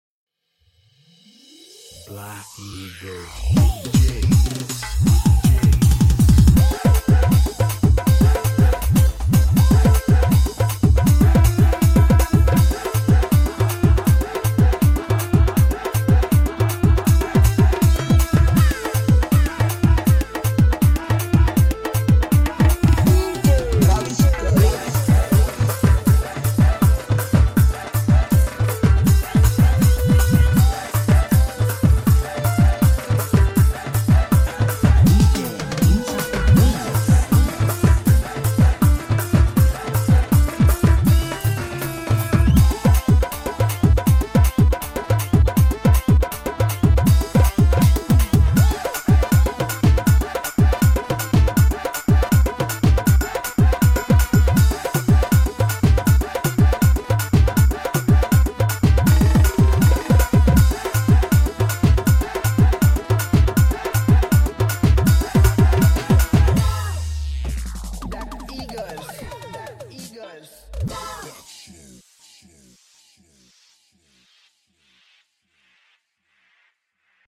High quality Sri Lankan remix MP3 (1.2).
Remix